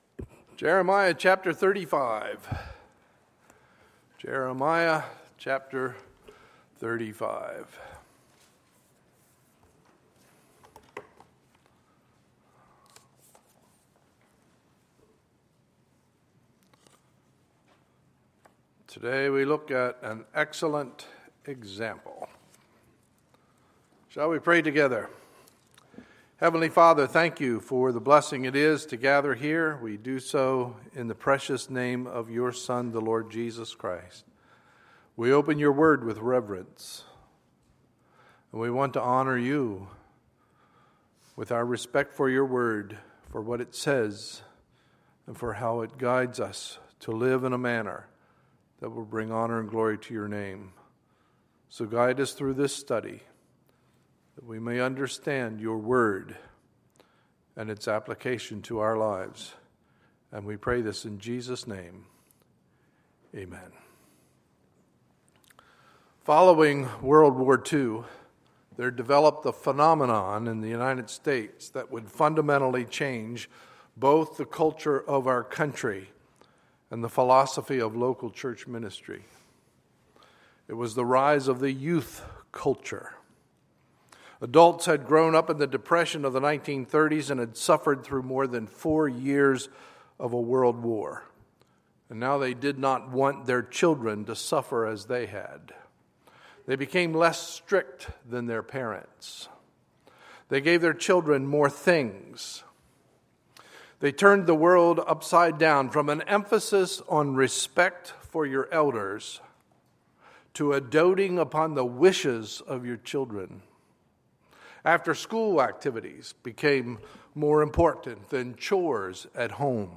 Sunday, August 16, 2015 – Sunday Morning Service